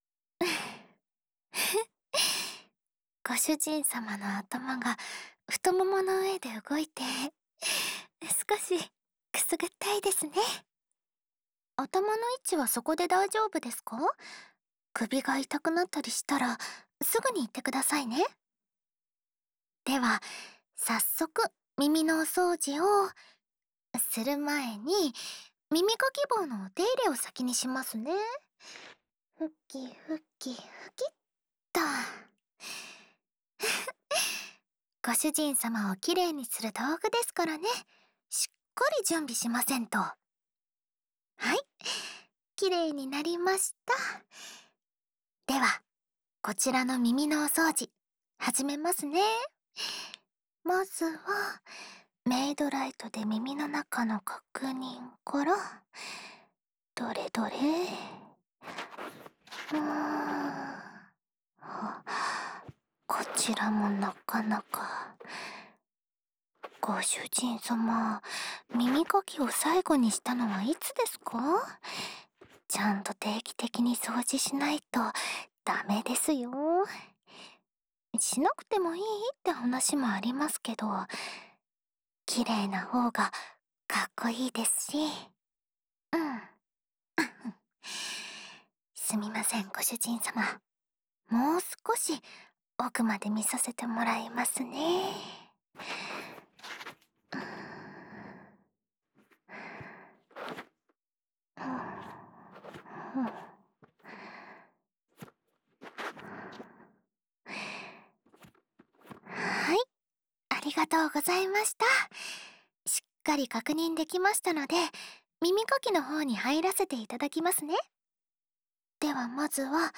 【メイドASMR】
mr03_06_『耳かきしますねご主人様（左）』.m4a